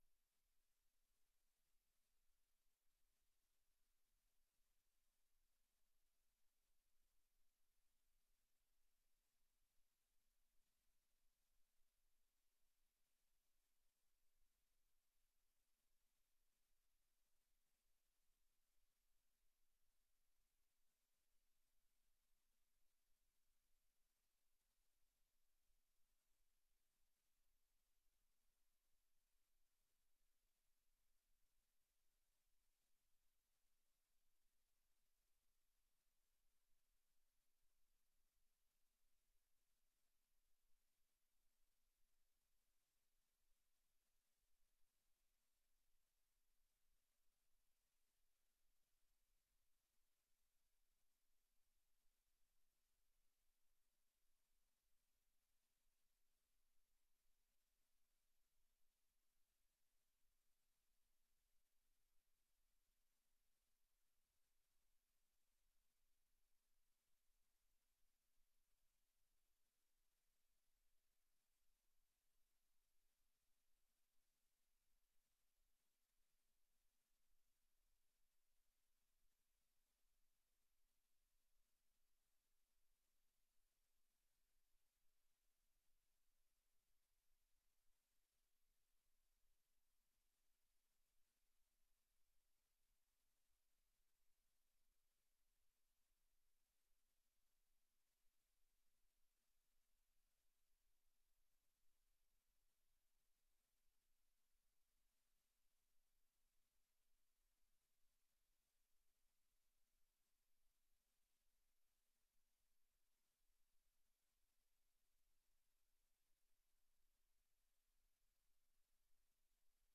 Gemeenteraad 26 september 2024 20:00:00, Gemeente Renkum
Download de volledige audio van deze vergadering